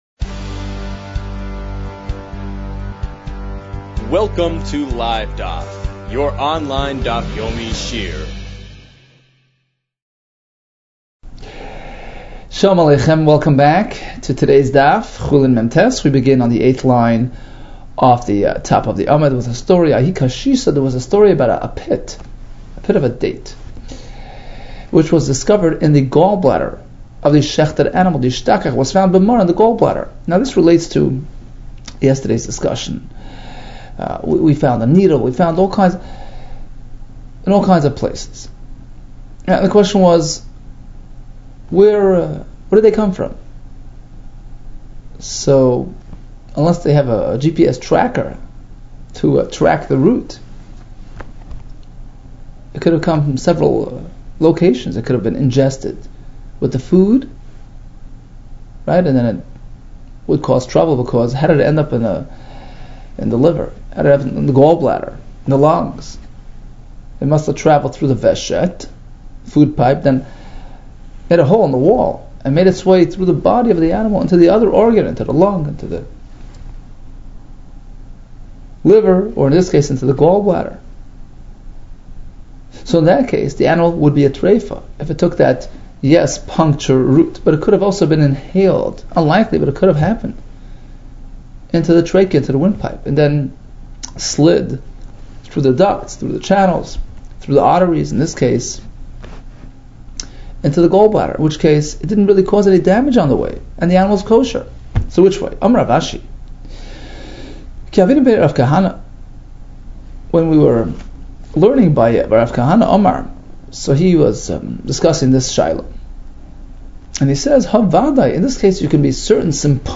Chulin 49 - חולין מט | Daf Yomi Online Shiur | Livedaf